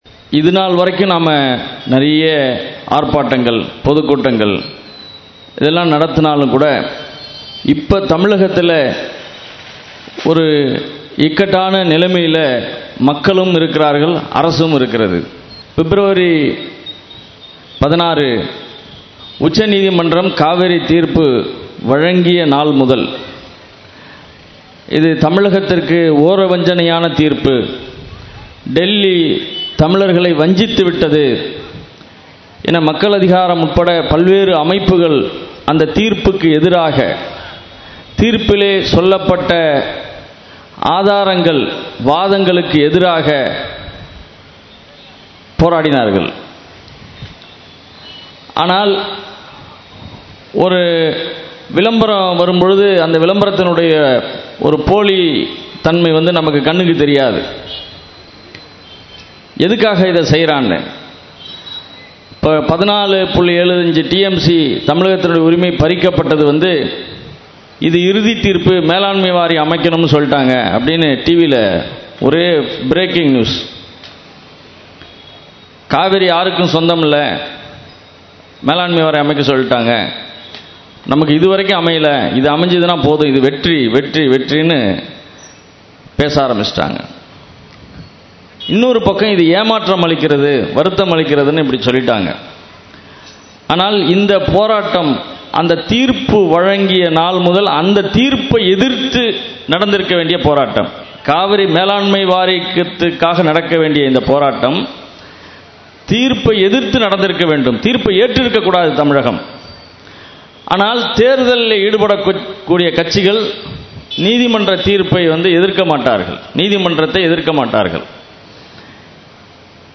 “ காவிரி உரிமை: குப்புறத் தள்ளிய டெல்லி குழியும் பறித்தது ! ’’ என்ற முழக்கத்தின் கீழ், ஏப்.28 அன்று சென்னை தாம்பரத்தில் பொதுக்கூட்டம் நடைபெற்றது.